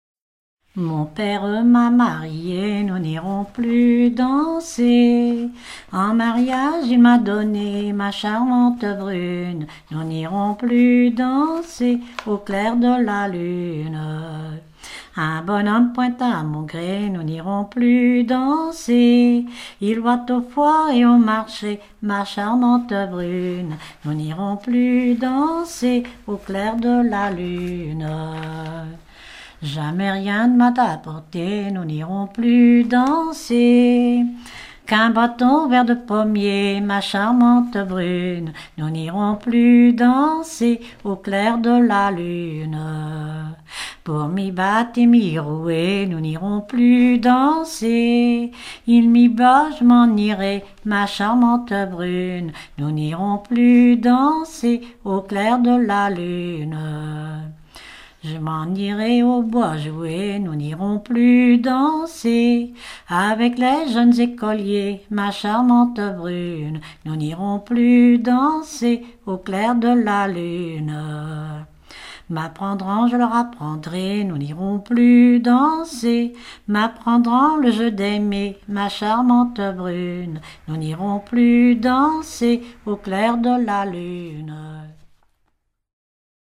Localisation Dompierre-sur-Yon
Genre brève